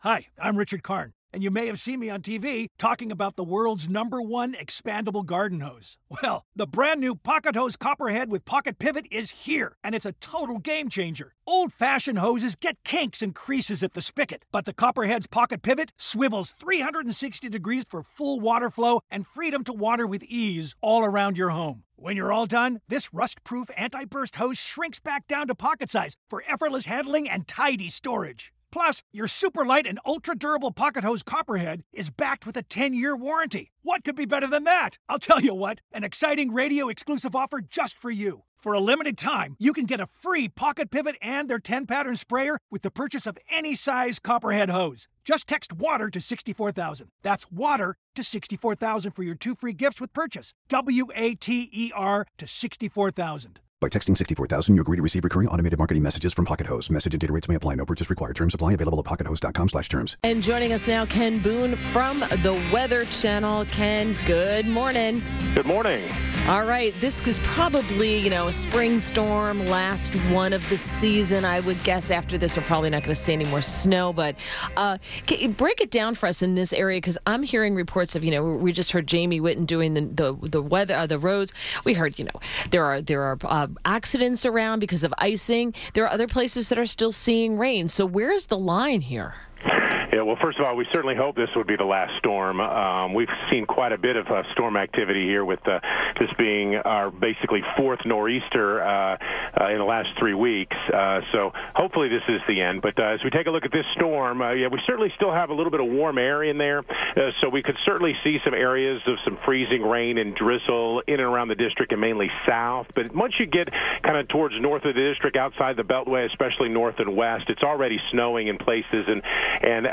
WMAL Interview
INTERVIEW